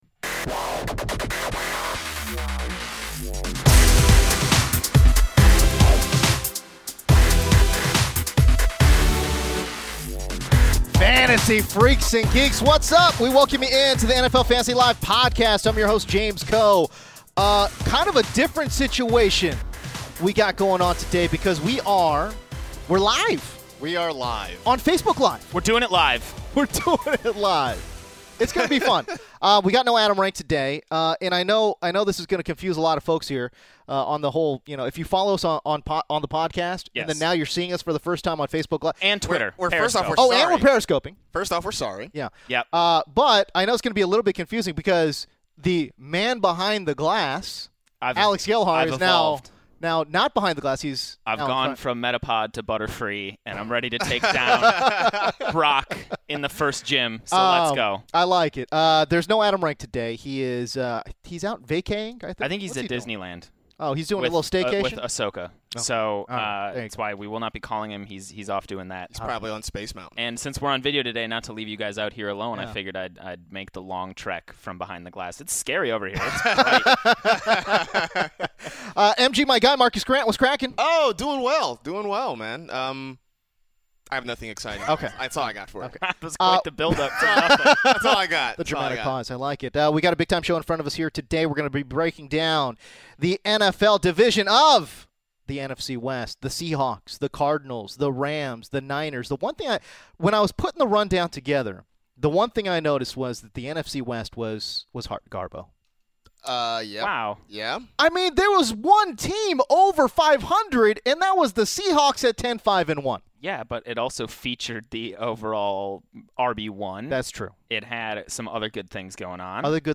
The NFL Fantasy LIVE crew assembles for a live show (on Facebook and Periscope) where they discuss the releases of Eric Decker and Jeremy Maclin, answer viewer questions, and dive into a breakdown of the NFC West. David Johnson's chances of repeating, where to draft Todd Gurley, trust issues with Eddie Lacy and more is discussed.